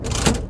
Lever.wav